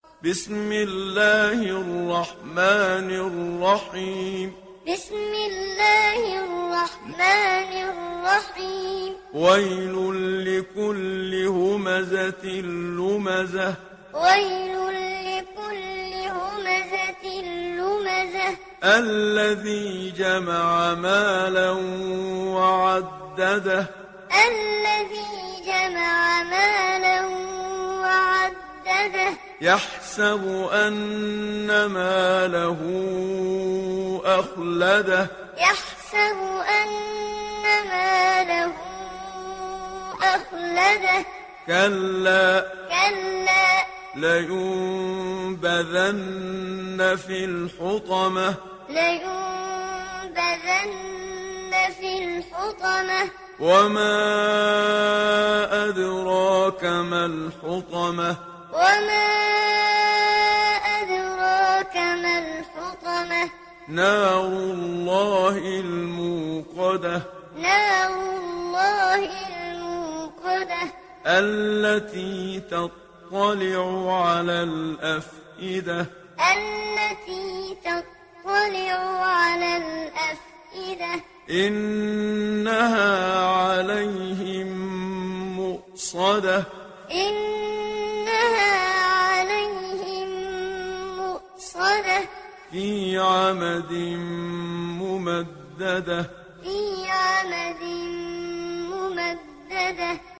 İndir Humeze Suresi Muhammad Siddiq Minshawi Muallim